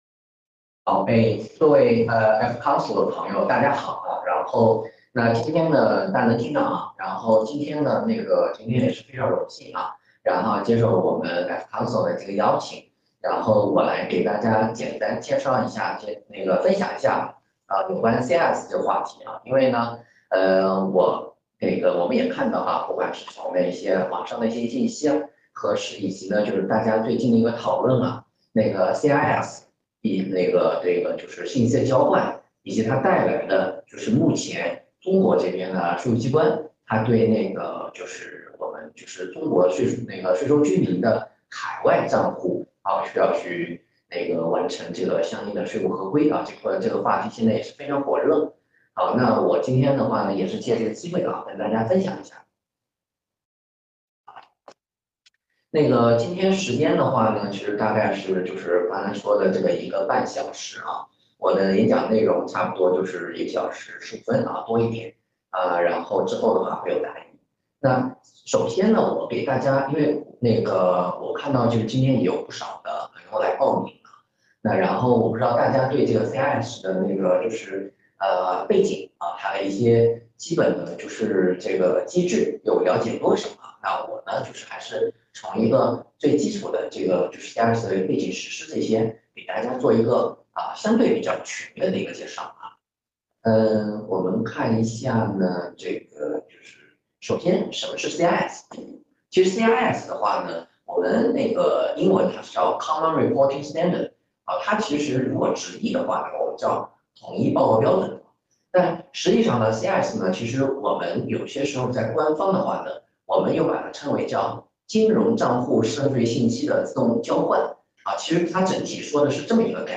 视频会议
8月14日【视频会议】CRS（共同申报准则）的应对建议.mp3